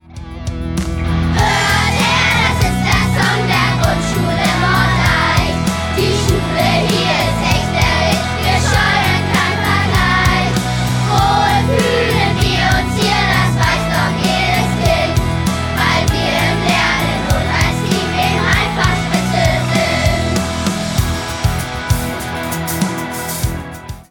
Gesungen von den Schülerinnen und Schülern